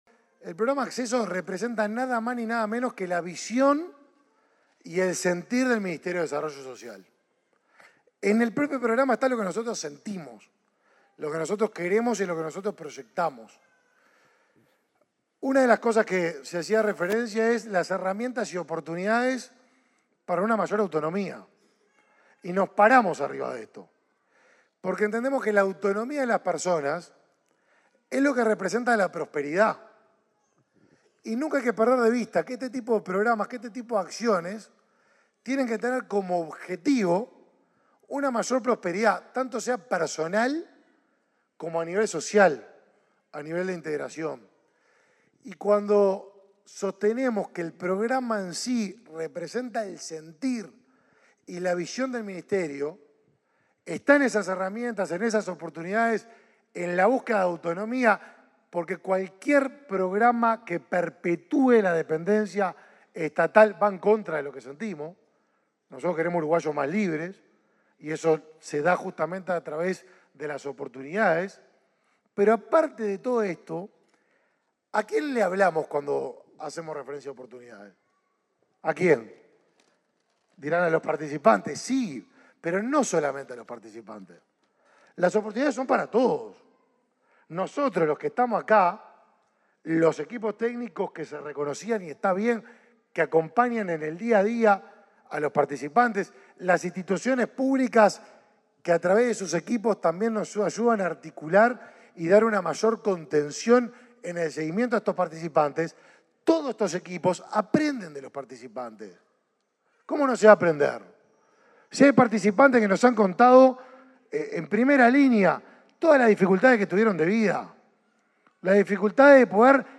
Palabras del ministro de Desarrollo Social, Martín Lema